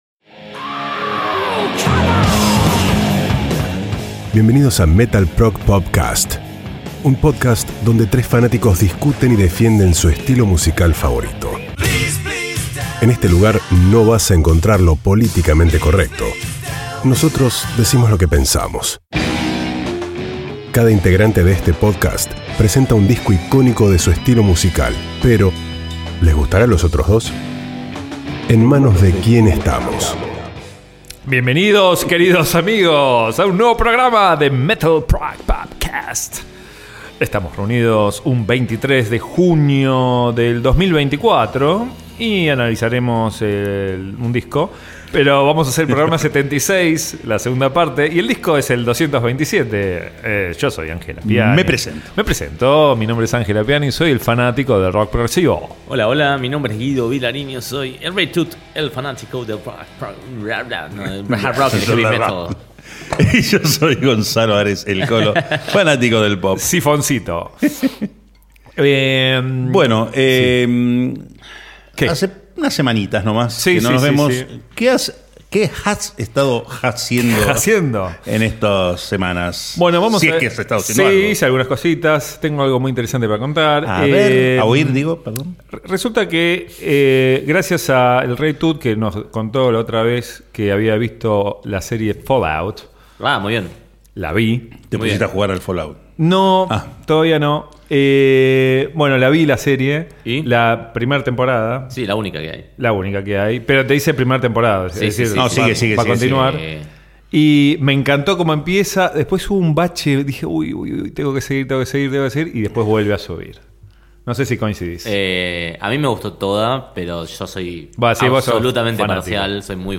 En MetalProgPop Cast nos juntamos 4 amigos para hablar y discutir sobre música.